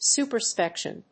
su・per・scrip・tion /sùːpɚskrípʃəns(j)ùːpə‐/
発音記号
• / sùːpɚskrípʃən(米国英語)